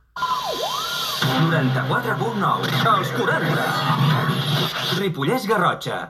Indicatiu de l'emissora
Banda FM